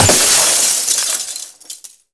glass_smashable_large_break_03.wav